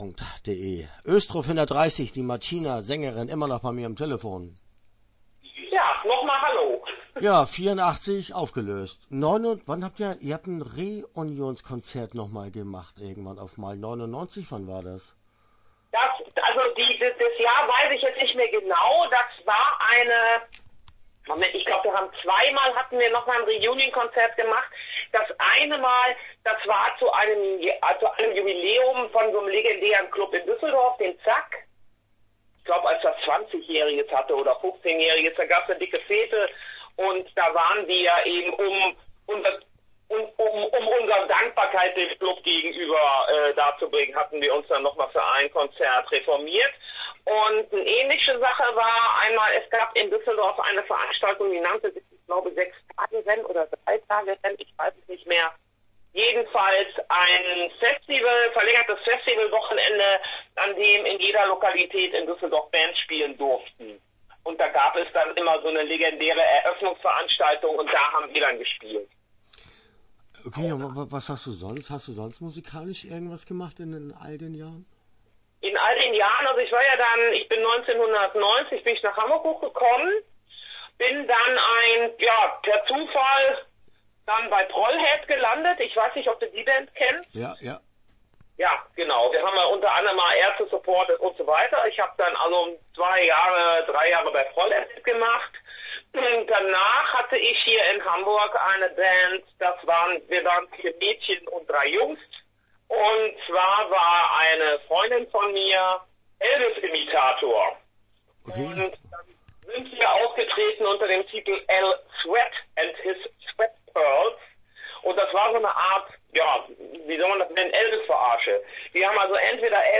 Östro 430 - Interview Teil 1 (10:03)